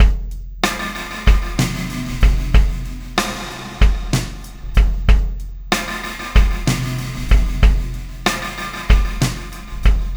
Pulsar Beat 15.wav